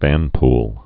(vănpl)